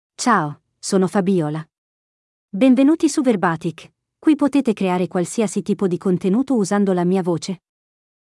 FabiolaFemale Italian AI voice
Fabiola is a female AI voice for Italian (Italy).
Voice sample
Female
Fabiola delivers clear pronunciation with authentic Italy Italian intonation, making your content sound professionally produced.